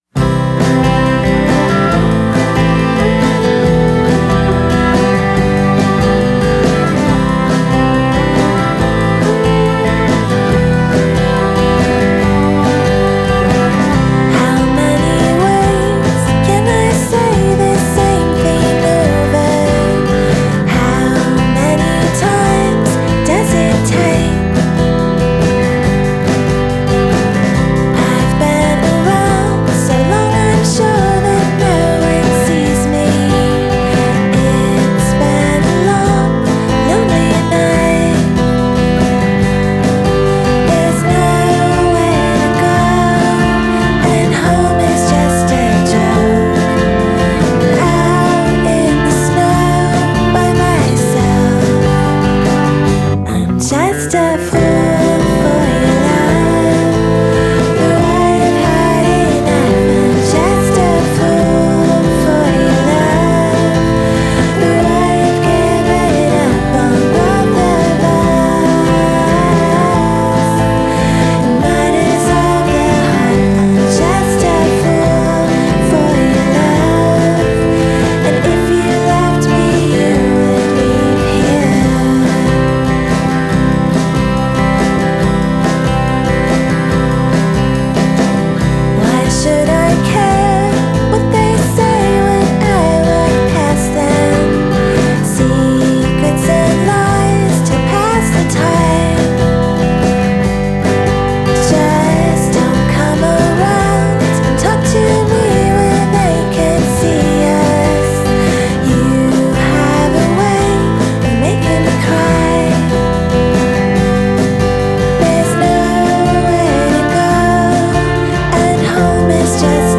vocals, acoustic guitar
drums
bass
viola, percussion, vocals
rhodes piano
Genre: Indie Pop / Twee / Female Vocal